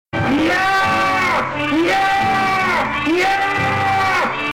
Merrill's Tarzan Cry: LISTEN
TarzanCryMerrill.mp3